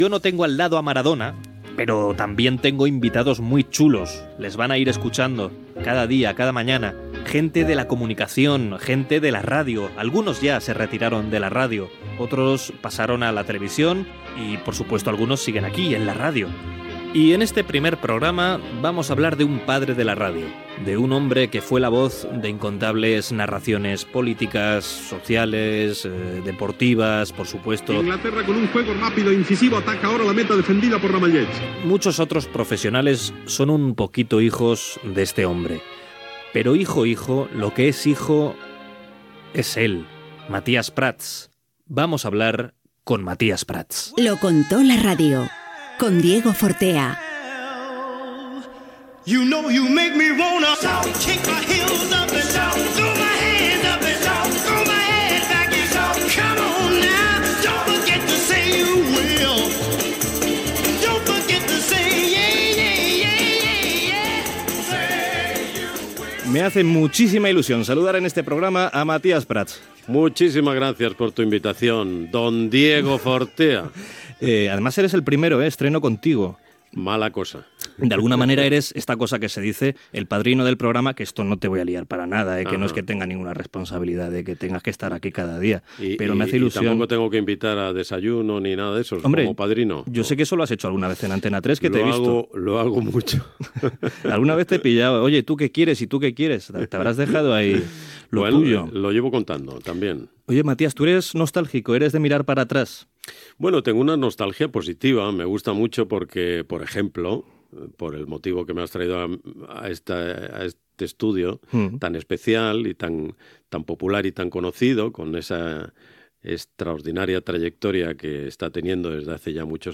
Fragment d'una entrevista al periodista Matías Prats (fill) sobre el seu pare, el locutor Matías Prats Cañete.